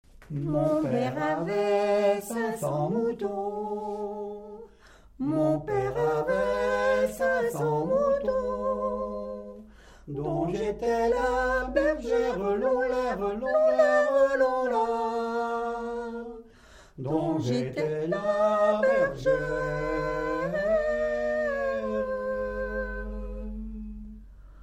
Mémoires et Patrimoines vivants - RaddO est une base de données d'archives iconographiques et sonores.
Genre laisse
Pièce musicale inédite